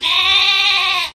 wooloo_ambient.ogg